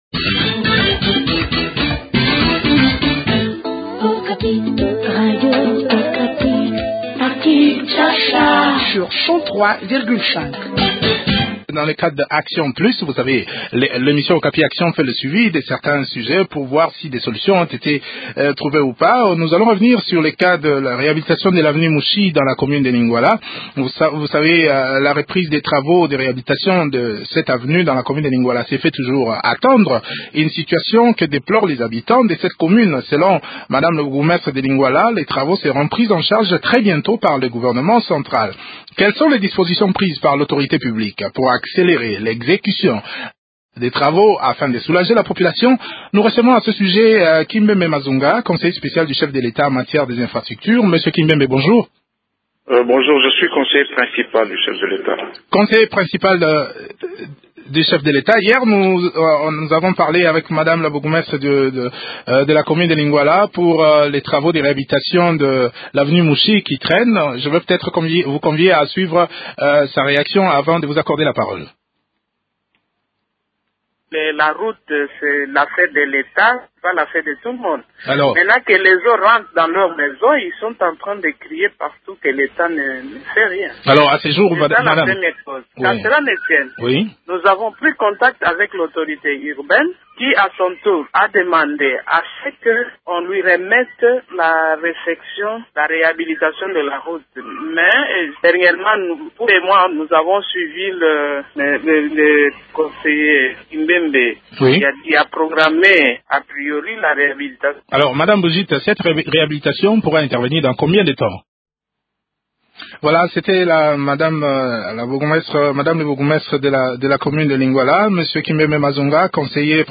en parle avec Kimbembe Mazunga, conseiller principal du chef de l’Etat en charge des infrastructures.